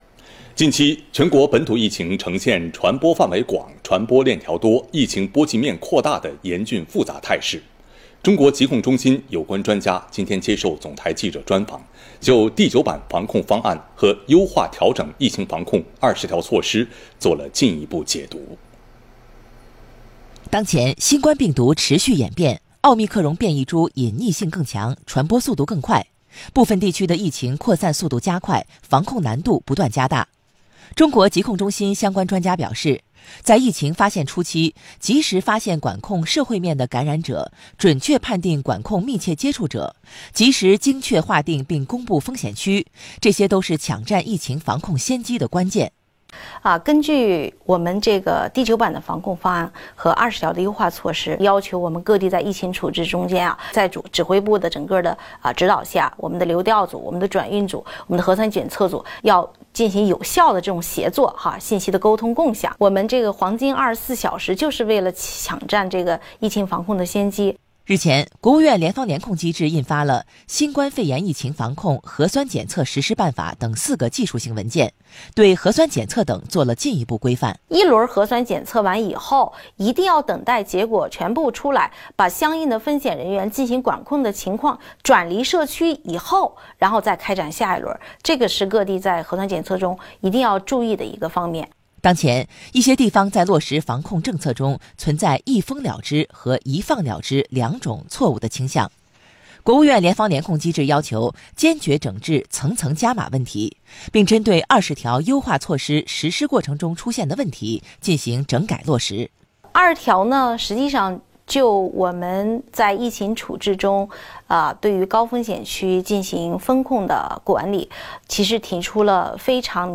中国疾控中心有关专家26日接受总台记者专访，进一步解读第九版防控方案和优化调整疫情防控二十条措施。